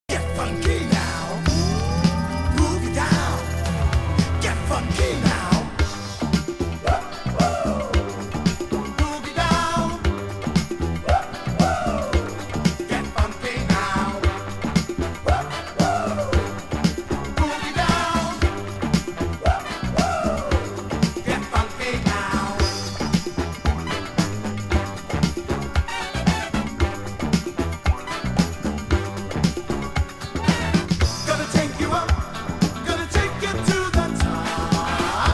躍動するビート、なんだかバブリーなグルーヴに圧倒されっぱなしだ。
(税込￥1980)   BOOGIE FUNK